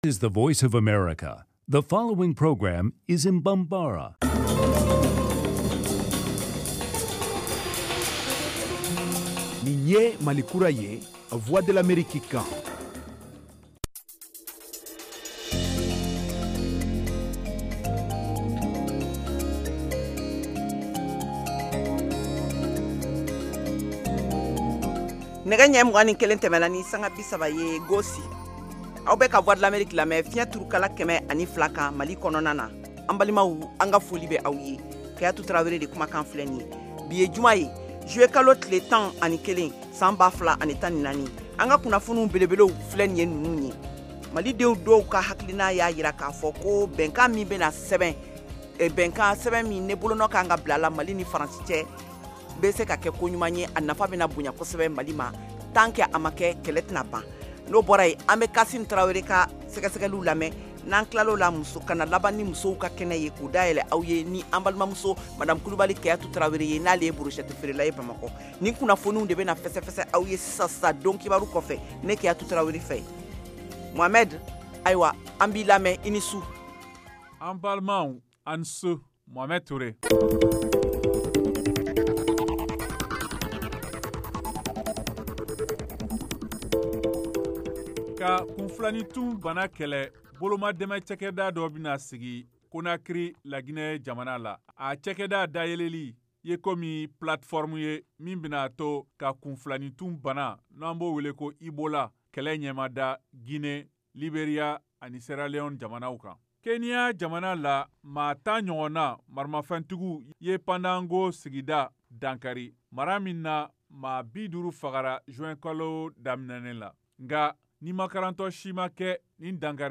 Emission quotidienne en langue bambara
en direct de Washington. Au menu : les nouvelles du Mali, les analyses, le sport et de l’humour.